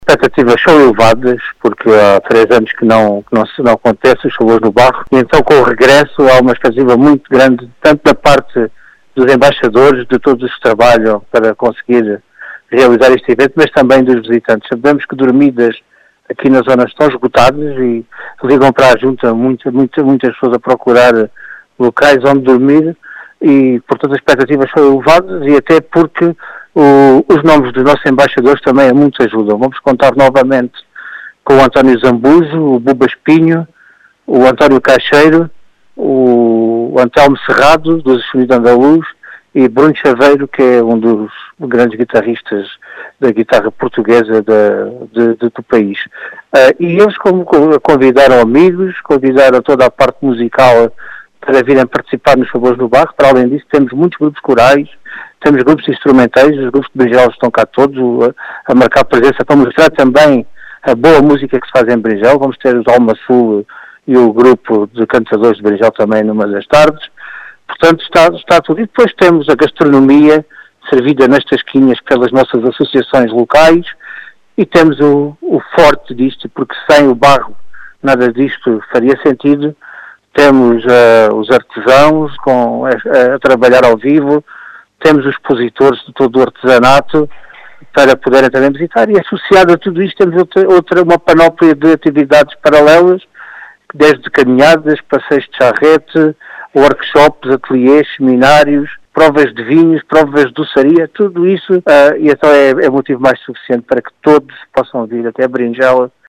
As explicações são de Vitor Besugo, presidente da junta de freguesia de Beringel, que diz ter “expectativas elevadas” para este regresso do certame.